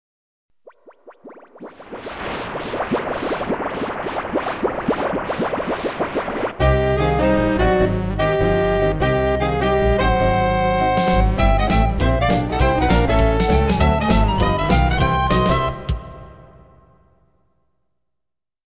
Roland MT-32 and Sound Canvas Enhanced version.